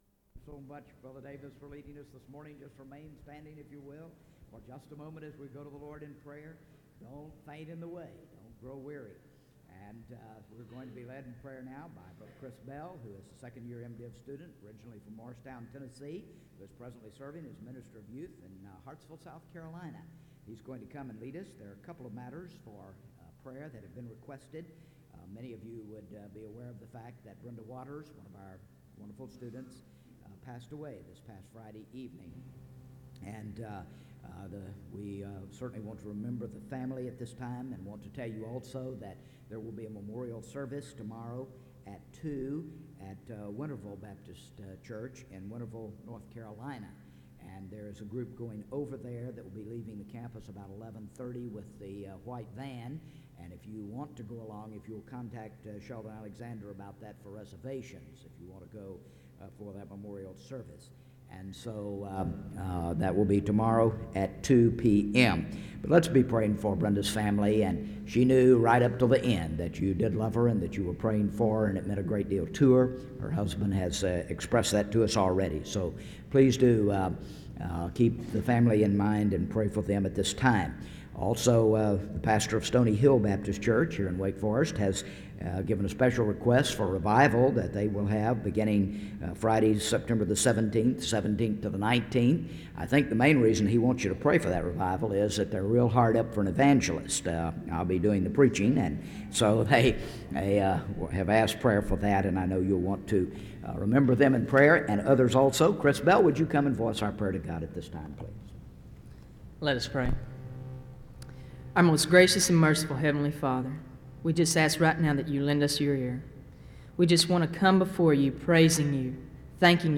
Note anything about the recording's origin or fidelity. Southeastern Baptist Theological Seminary SEBTS Chapel and Special Event Recordings